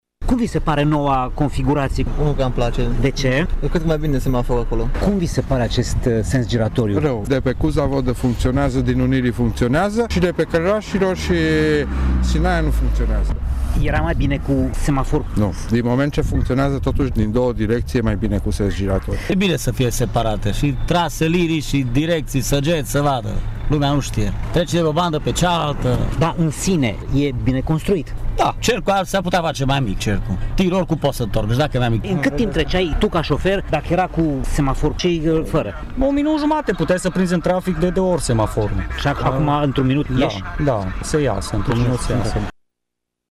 Nu toți șoferii sunt mulțumiți de modul în care este proiectat acest sens giratoriu, însă cei mai mulți sunt de acord că e mult mai bine decât atunci când zona era semaforizată: